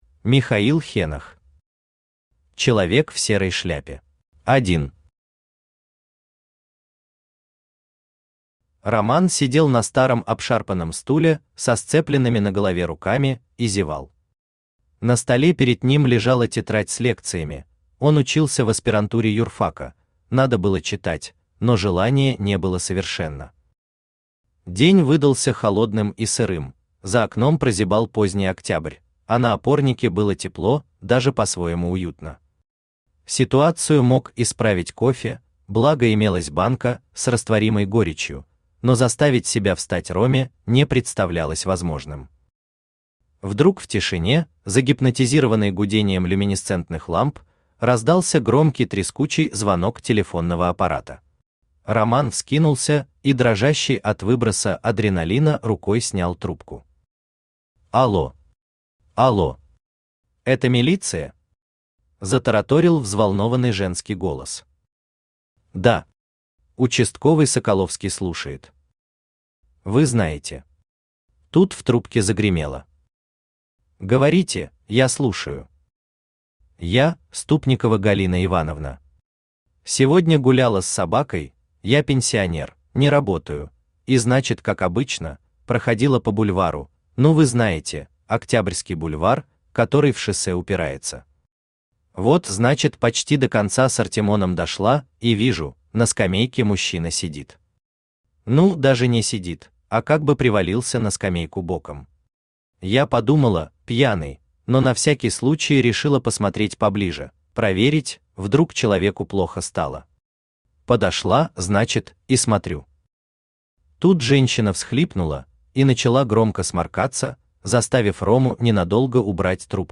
Аудиокнига Человек в серой шляпе | Библиотека аудиокниг
Aудиокнига Человек в серой шляпе Автор Михаил Витальевич Хенох Читает аудиокнигу Авточтец ЛитРес.